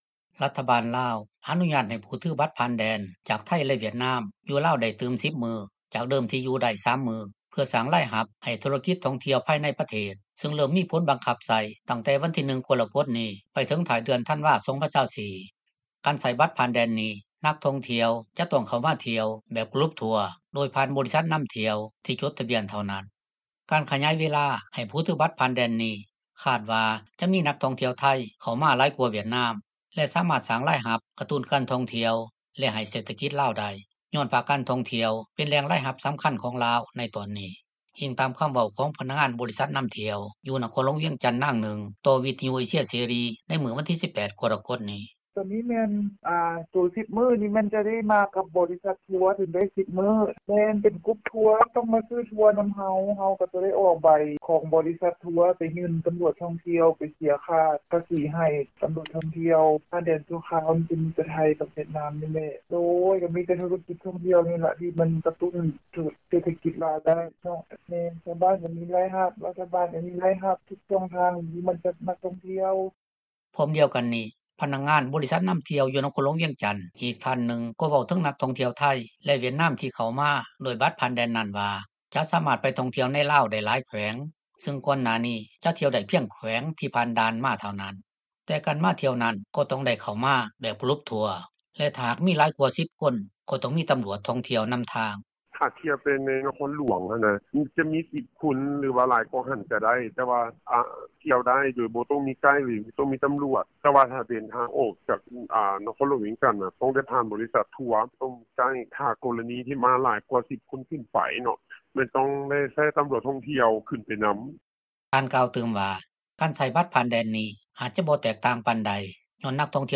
ພ້ອມດຽວກັນນີ້ ພະນັກງານ ບໍລິສັດນໍາທ່ຽວຢູ່ນະຄອນຫລວງວຽງຈັນ ອີກທ່ານໜຶ່ງ ກໍເວົ້າເຖິງນັກທ່ອງທ່ຽວໄທ ແລະຫວຽດນາມ ທີ່ເຂົ້າມາດ້ວຍບັດຜ່ານແດນນັ້ນວ່າ ຈະສາມາດໄປທ່ອງທ່ຽວໃນລາວໄດ້ຫລາຍແຂວງ ຊຶ່ງກ່ອນໜ້ານີ້ ຈະທ່ຽວໄດ້ ພຽງແຂວງ ທີ່ຜ່ານດ່ານມາເທົ່ານັ້ນ. ແຕ່ການມາທ່ຽວນັ້ນ ກໍຕ້ອງເຂົ້າມາແບບກະລຸບທົວຣ໌ ແລະຖ້າຫາກມີຫຼາຍກວ່າ 10 ຄົນ ກໍຕ້ອງມີຕໍາຫຼວດທ່ອງທ່ຽວ ນໍາທາງ.
ຢູ່ນະຄອນຫລວງວຽງຈັນ ພະນັກງານເຮືອນພັກນາງໜຶ່ງ ເວົ້າຕໍ່ວິທຍຸເອເຊັຽເສຣີ ໃນມື້ດຽວກັນນີ້ ເຖິງມາດຕະການດັ່ງກ່າວວ່າ ໜ້າຈະສາມາດ ກະຕຸ້ນລາຍຮັບໃຫ້ທຸລະກິດທ່ອງທ່ຽວໄດ້ ໂດຍສະເພາະໂຮງແຮມ ໃກ້ຊາຍແດນ ເປັນຕົ້ນໂຮງ ແຮມ ທີ່ຢູ່ແຄມແມ່ນໍ້າຂອງ.